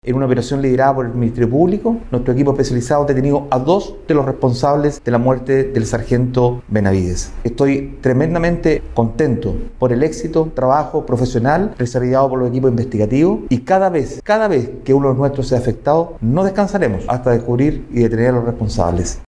Así mismo, el General de Carabineros de Chile, Ricardo Yañez, señaló que cada vez que un uniformado sea atacado u herido, no se detendrán hasta dar con quienes sean responsables.